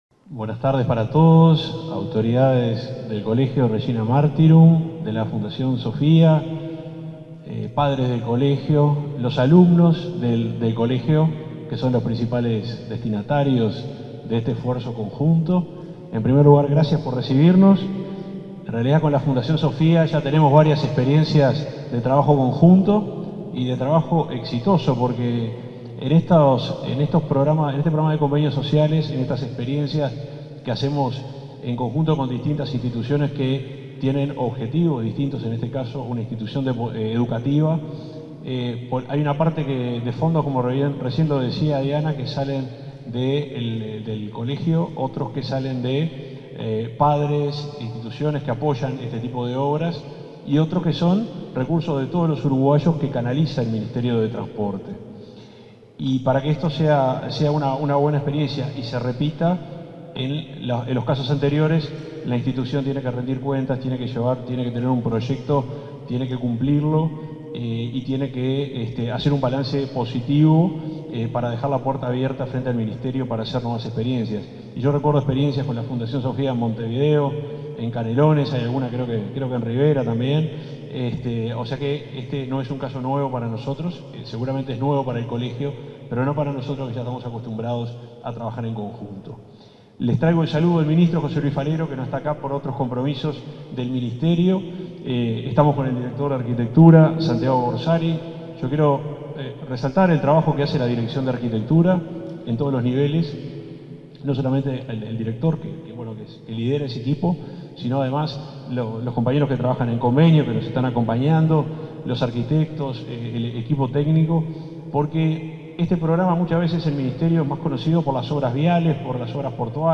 Palabras del subsecretario de Transporte y Obras Públicas, Juan José Olaizola
Tras la firma de un convenio social con la Fundación Sophia, para refaccionar el gimnasio del colegio Regina de Martyrum, este 3 de agosto, se expresó